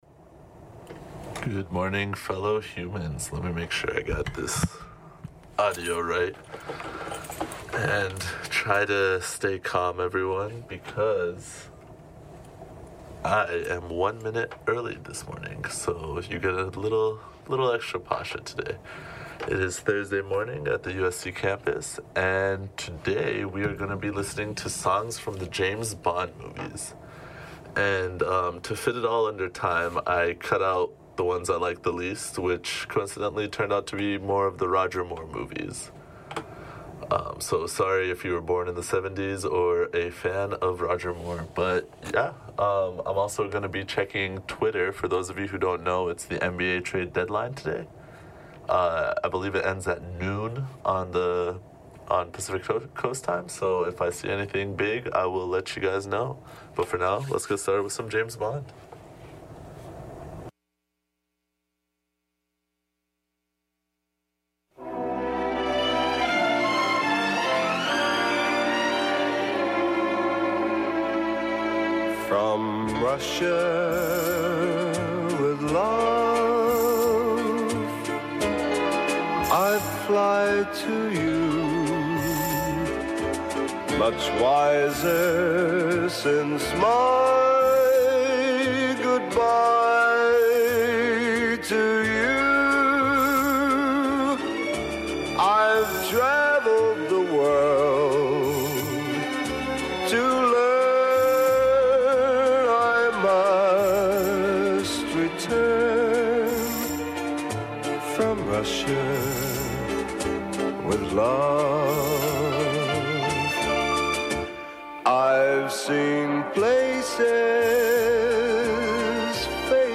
*This is a recording of a live show from the non-profit station, KXSC.